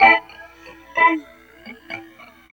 71 GTR 1  -L.wav